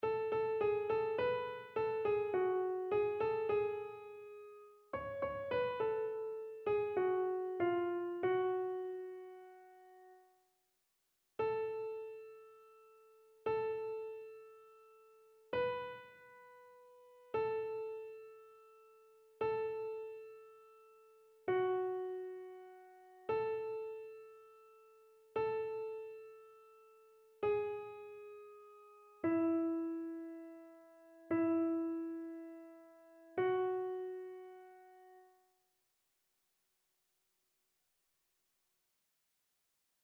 Chœur
annee-c-temps-de-noel-bapteme-du-seigneur-psaume-103-soprano.mp3